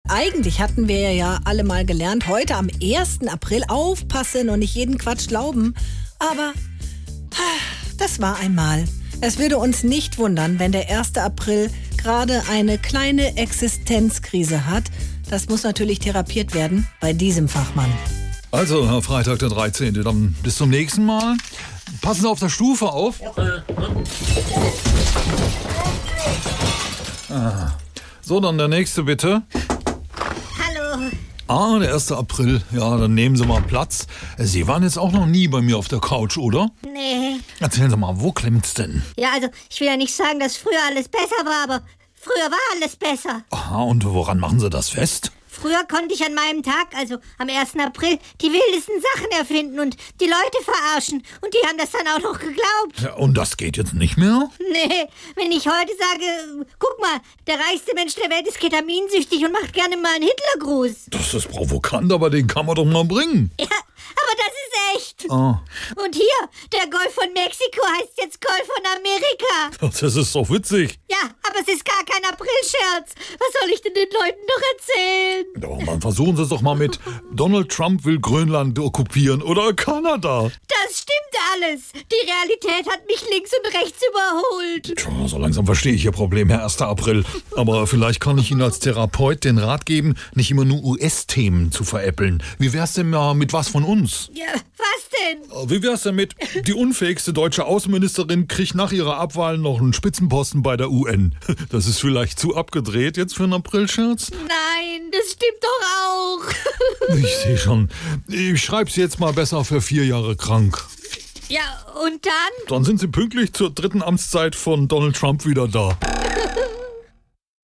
SWR3 Comedy Der erste April auf der Couch